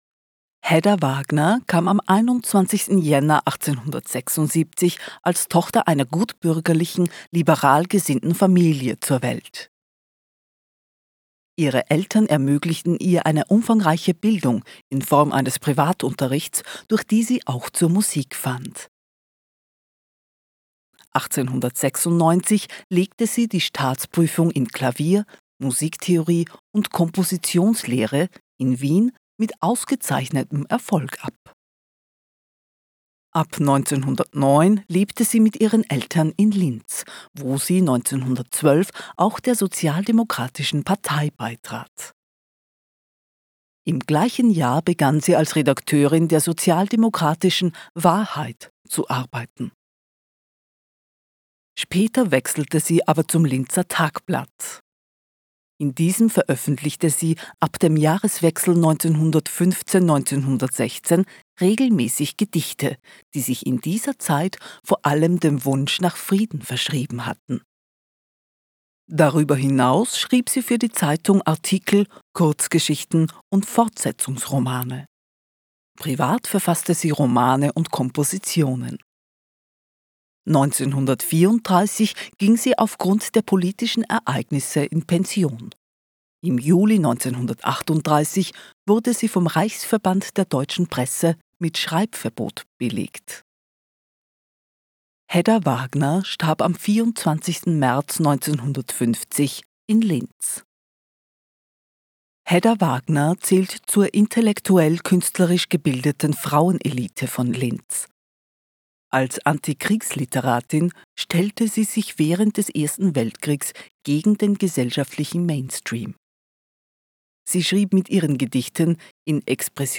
Audioguide Hedda Wagner Englisch